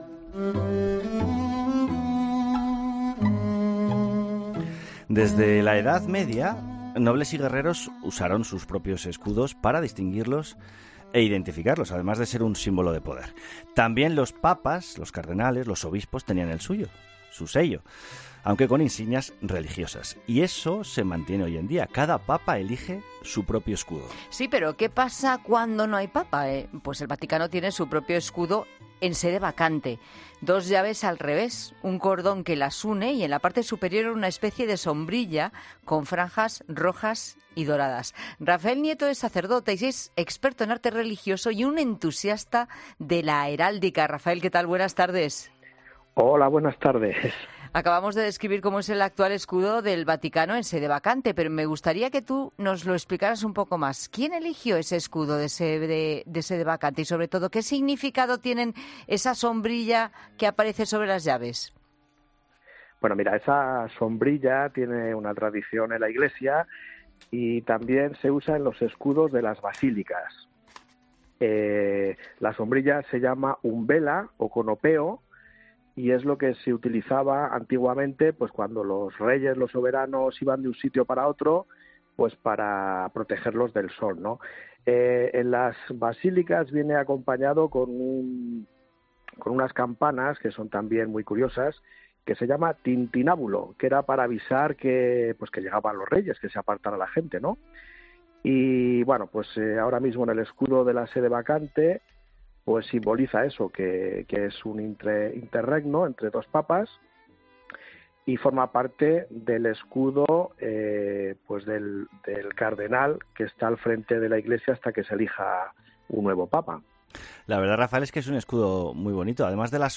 La entrevista, emitida en el programa Mediodía COPE, partió de una pregunta clave: ¿qué escudo se utiliza cuando la Iglesia está en sede vacante?